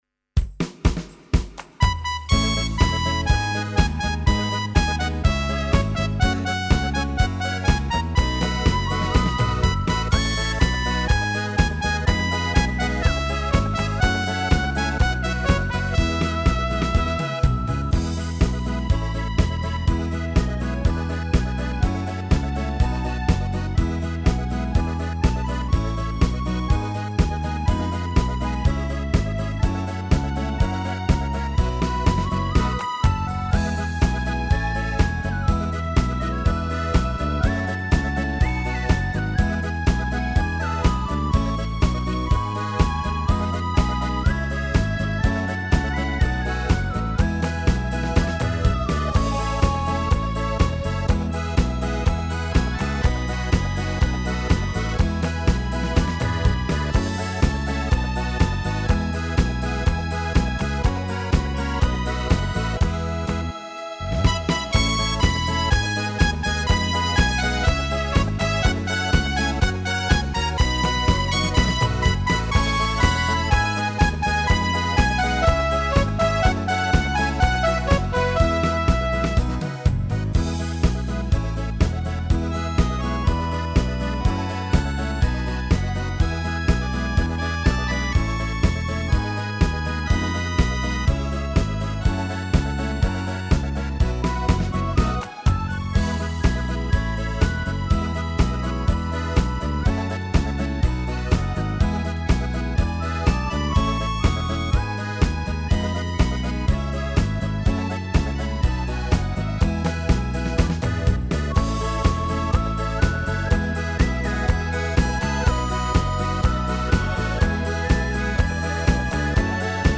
Tone Tốp (E)
•   Beat  01.